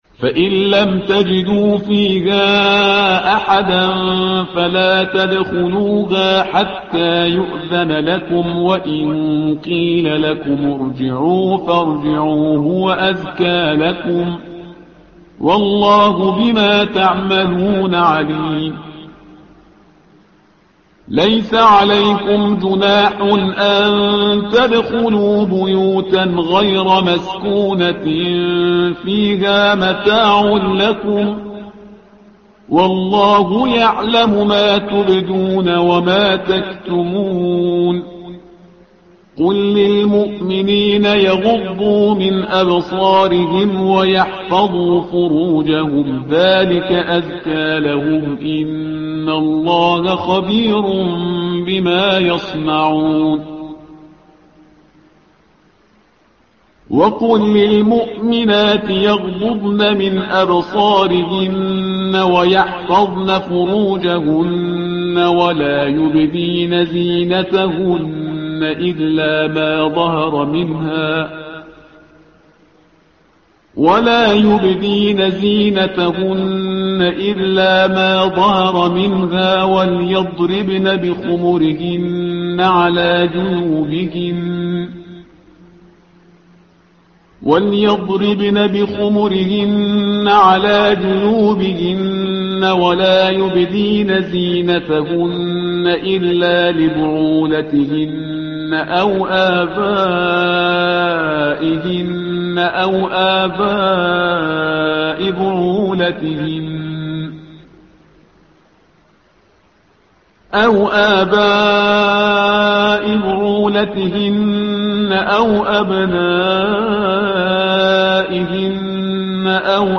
الصفحة رقم 353 / القارئ